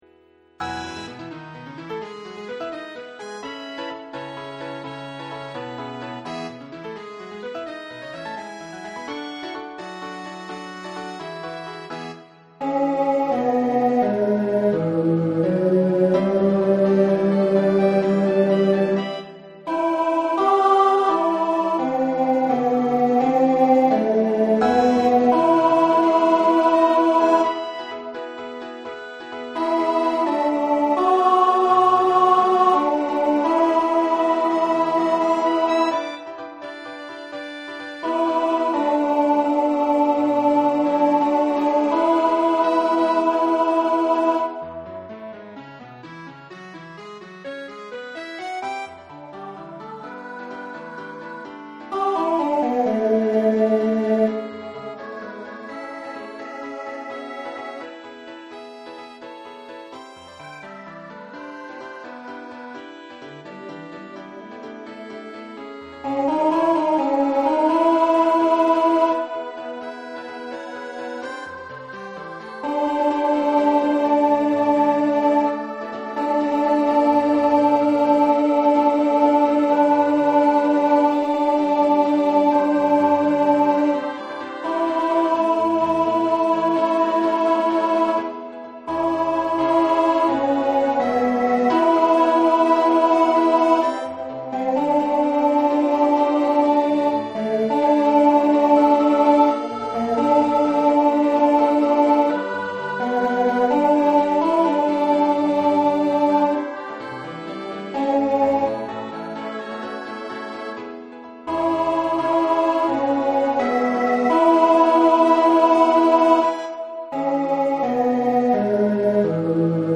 Tenor Practice Files